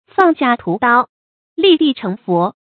注音：ㄈㄤˋ ㄒㄧㄚˋ ㄊㄨˊ ㄉㄠ ，ㄌㄧˋ ㄉㄧˋ ㄔㄥˊ ㄈㄛˊ
放下屠刀，立地成佛的讀法